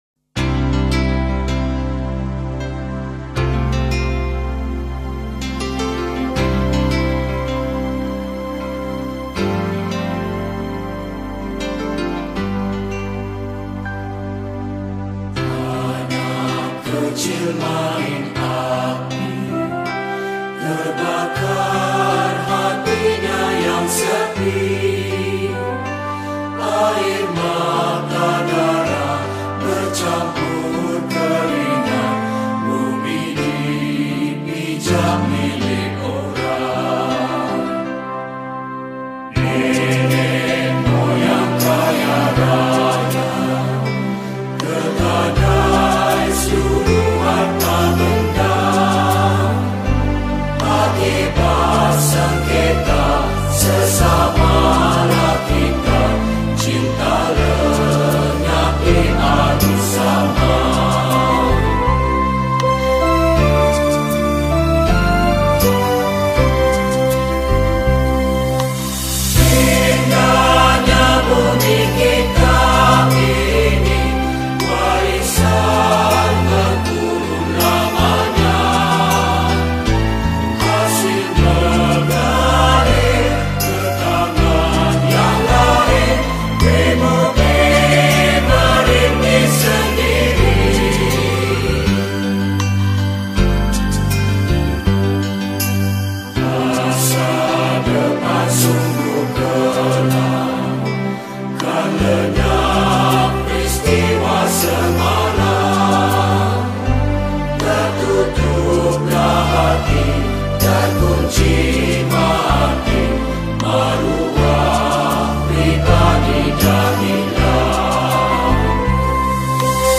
Malay Patriotic Song
Solo Recorder